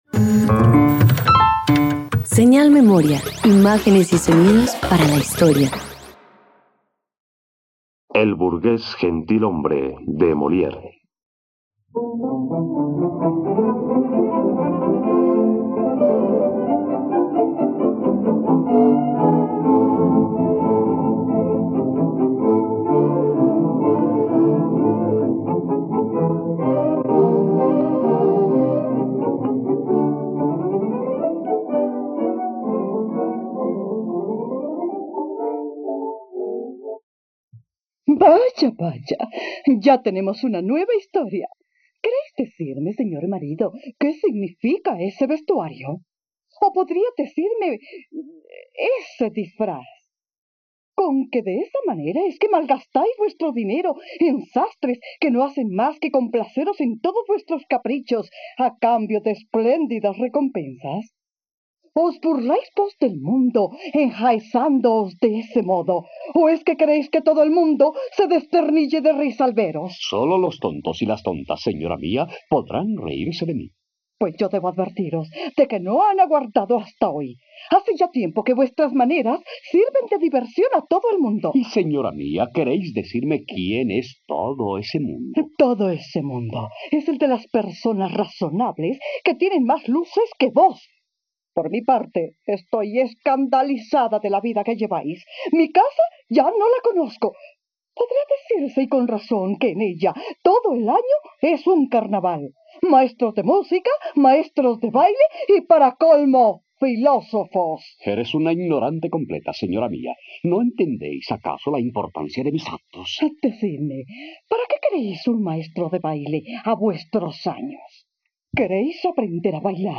..Radioteatro. Escucha la adaptación de la obra "El burgués gentilhombre" del dramaturgo, actor y poeta francés Molière en la plataforma de streaming RTVCPlay.